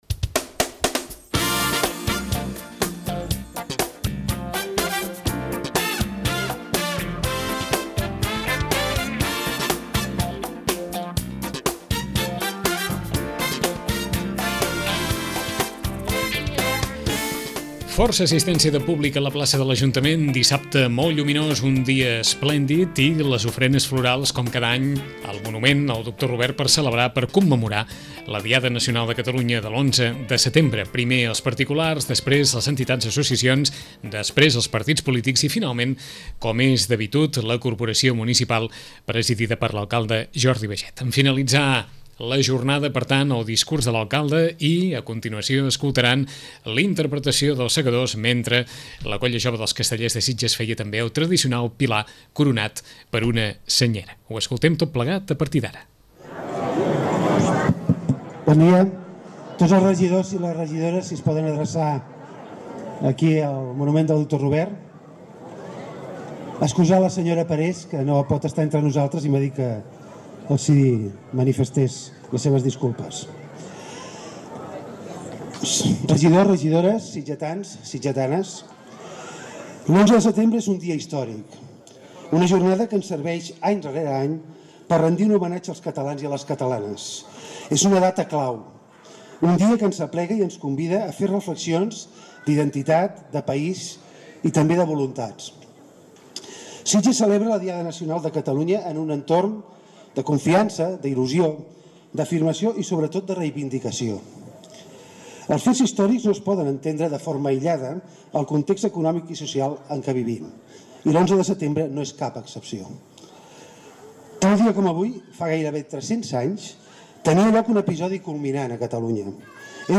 Us oferim l’acte institucional d’ofrena al monument del doctor Bartomeu Robert, amb motiu de la Diada Nacional de Catalunya, i la interpretació que el grup Vox Populi féu de la Moixiganga de Sitges, durant la commemoració al parc de la Ciutadella de Barcelona.